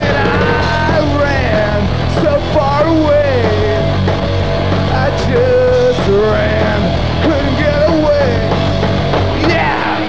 Very driving.
d: it's got the big rock sound
d: ah, the classic scream at the end
d: yeah, itwas very straightahead punk rock.